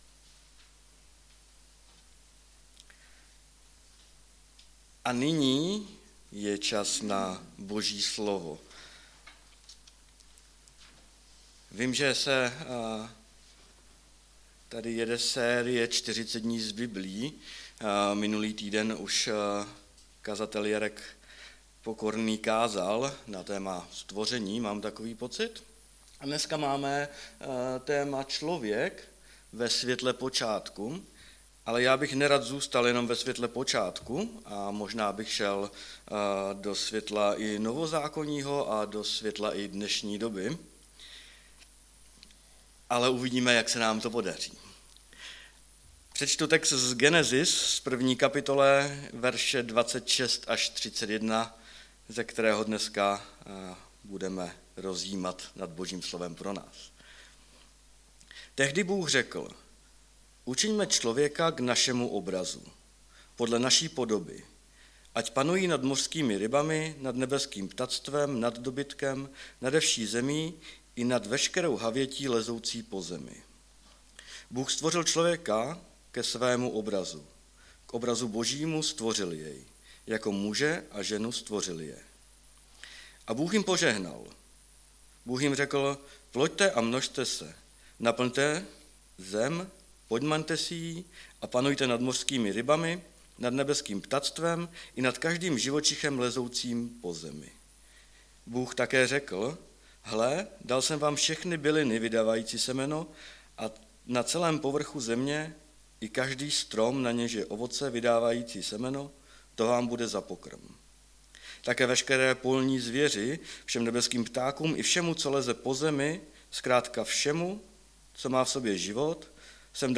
3. díl ze série kázání "Ve světle počátku", Gn 1,26-31